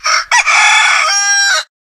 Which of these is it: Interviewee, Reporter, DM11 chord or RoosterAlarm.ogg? RoosterAlarm.ogg